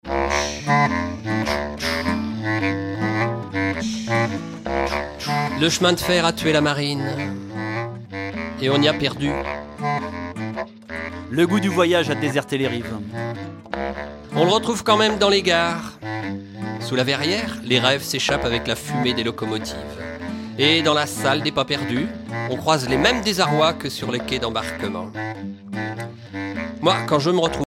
Concert donné en 2004
Pièce musicale inédite